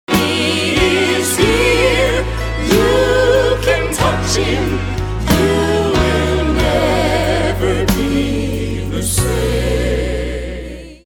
6 vocal numbers